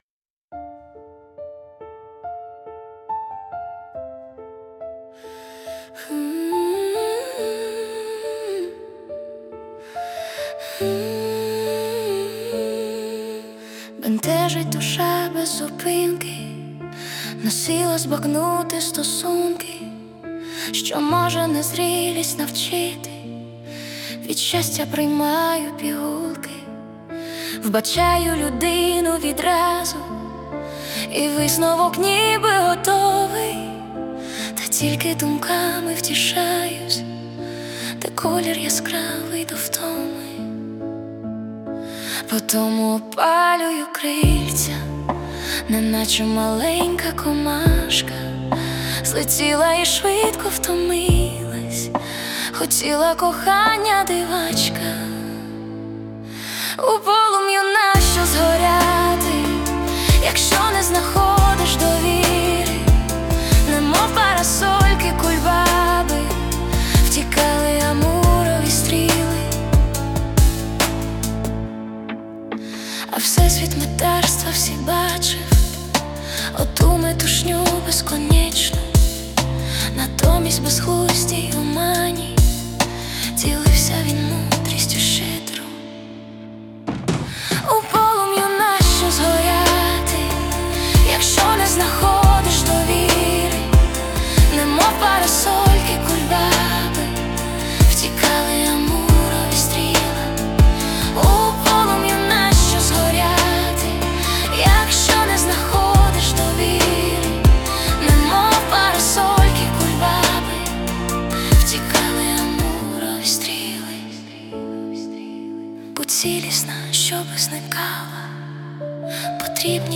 Музична композиція створена за допомогою SUNO AI
СТИЛЬОВІ ЖАНРИ: Ліричний
Така чарівно-щемна пісня, яка торкає своє простотою та чуттєвістю.